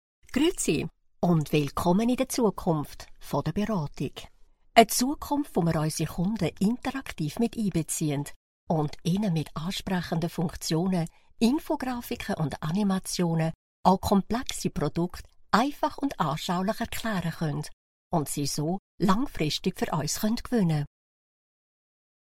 locutora suiza
Swiss German voice over